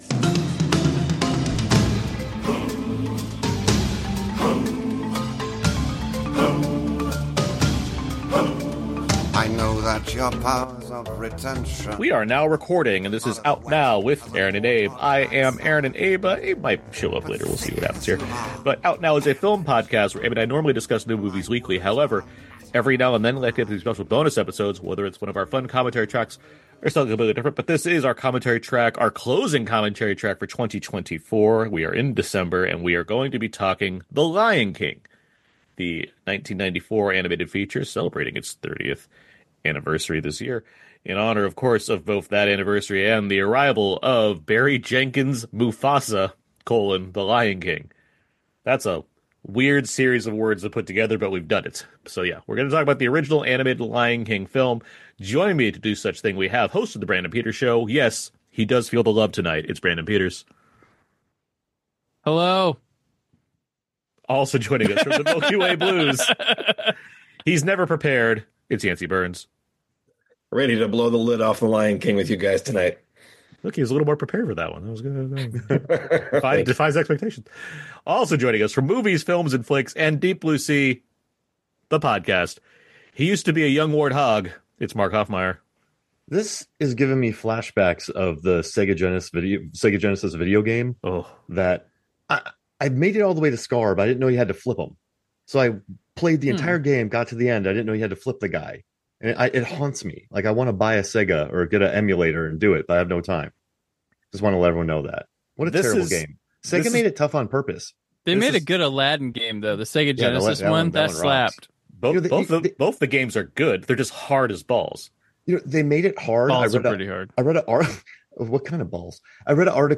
Out Now Commentary: The Lion King (1994)